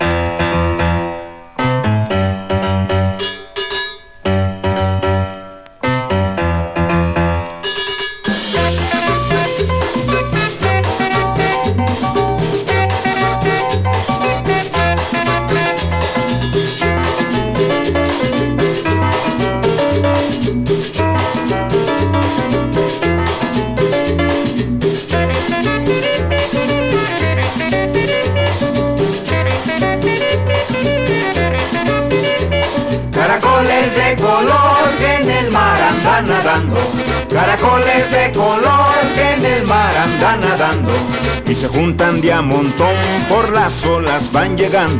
Latin, Cumbia, Boogaloo　USA　12inchレコード　33rpm　Mono